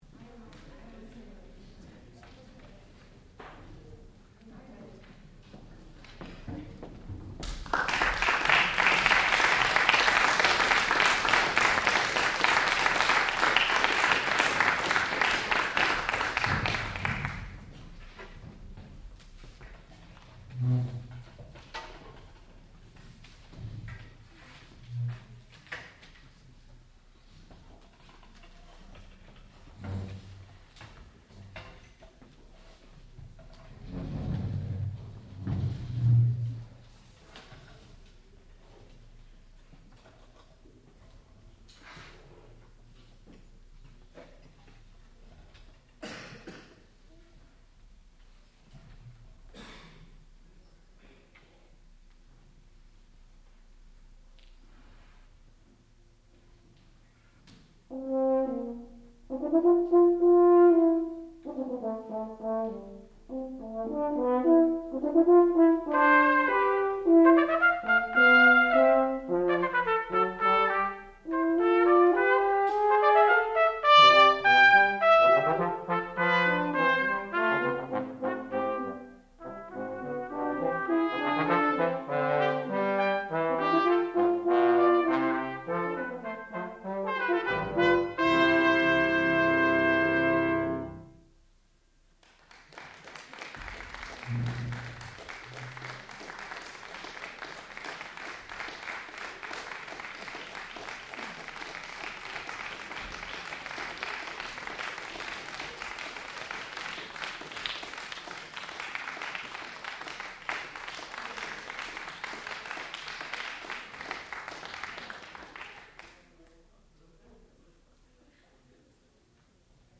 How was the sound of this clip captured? Master's Recital